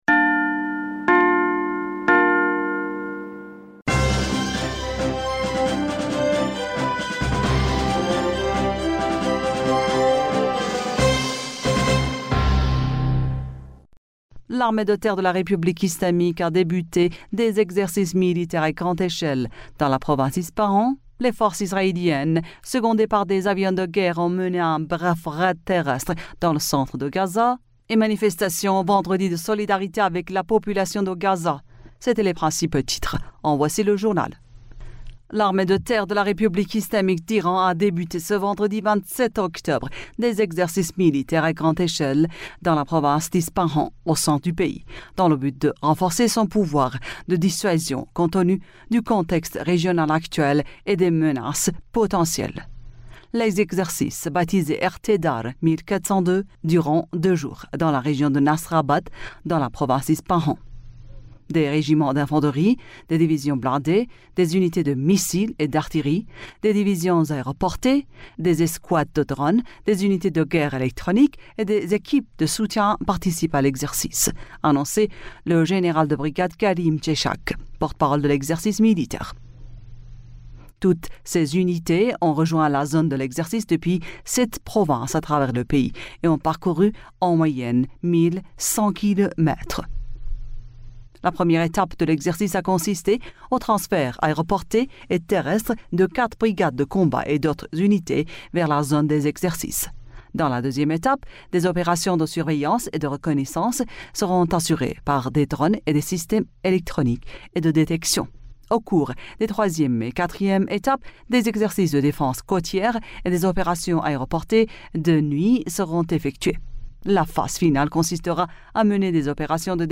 Bulletin d'information du 27 Octobre 2023